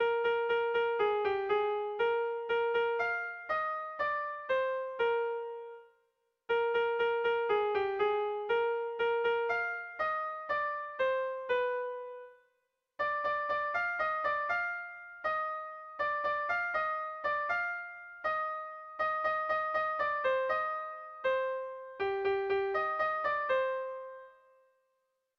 Sentimenduzkoa
Zortziko ertaina (hg) / Lau puntuko ertaina (ip)
AABD